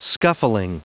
Prononciation du mot scuffling en anglais (fichier audio)
Prononciation du mot : scuffling